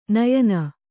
♪ nayana